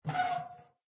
mailbox_open_1.ogg